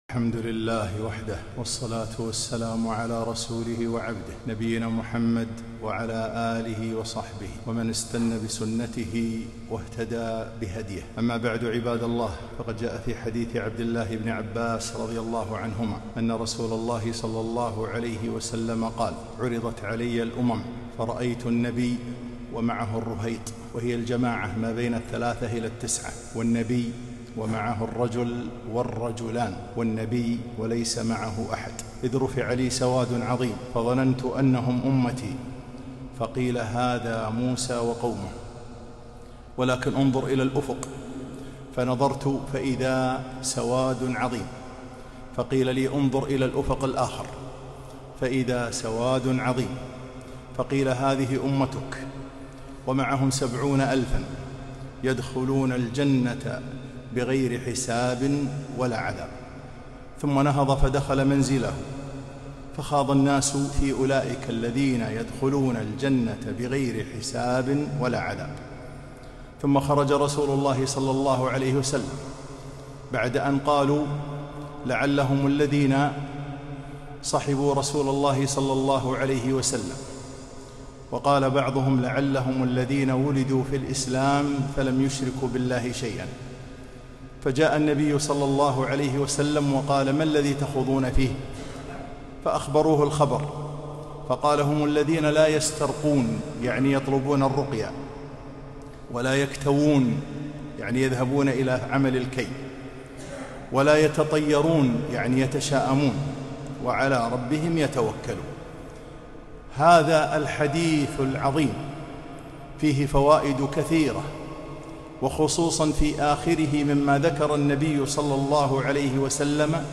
خطبة - من أسباب الفوز والنجاة